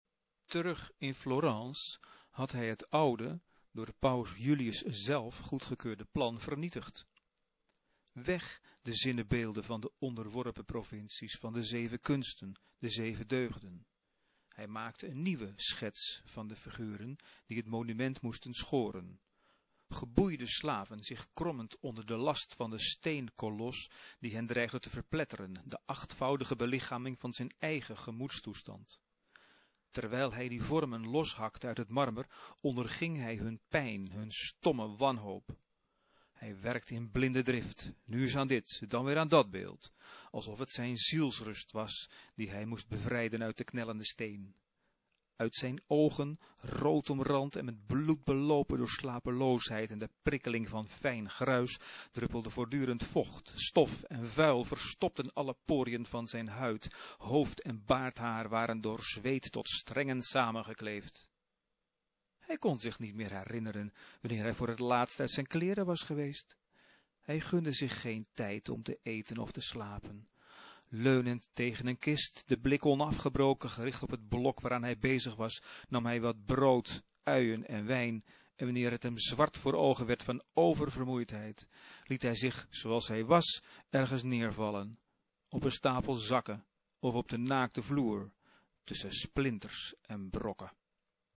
Betekent: het fragment wordt voorgelezen. (MP-3)